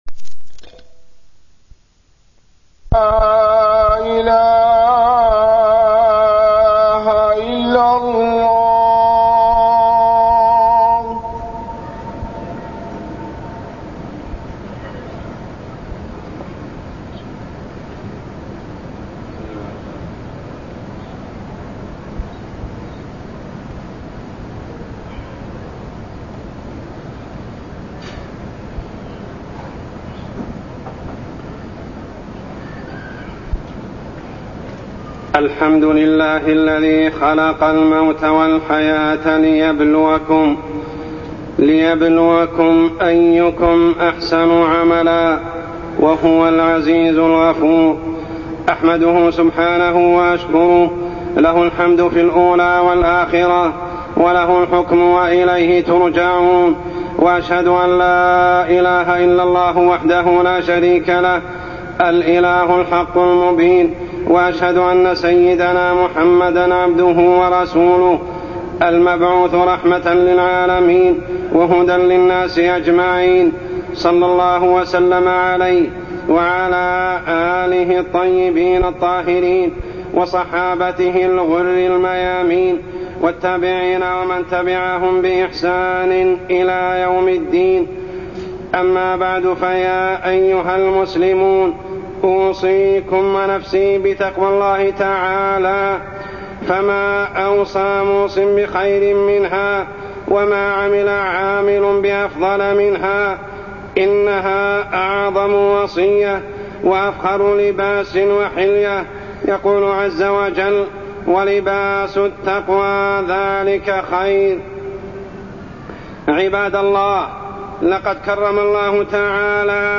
تاريخ النشر ٨ ذو القعدة ١٤٢١ هـ المكان: المسجد الحرام الشيخ: عمر السبيل عمر السبيل حفظ النفس